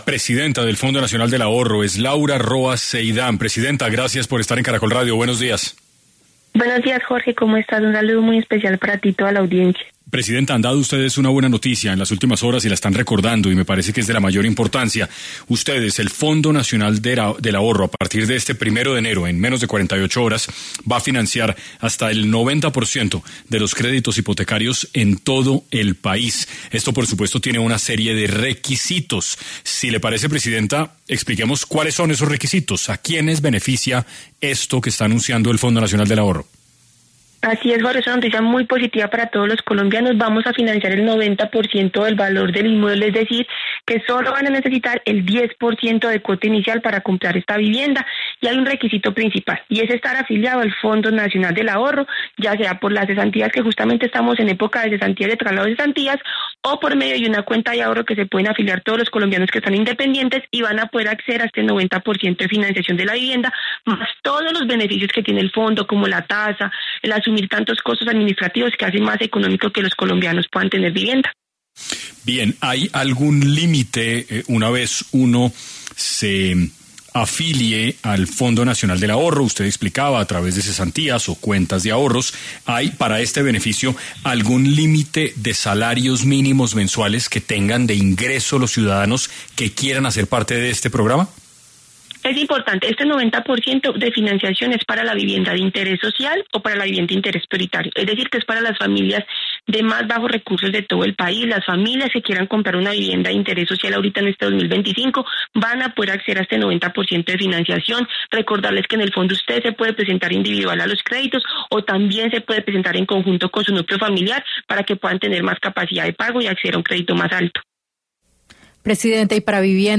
En 6AM de Caracol Radio estuvo Laura Roa Zeidán, Presidenta Fondo Nacional del Ahorro, quien habló sobre cuál será la nueva financiación de los créditos hipotecarios que implementarán a partir del 1 de enero y a quiénes beneficiará.
Entrevista completa aquí: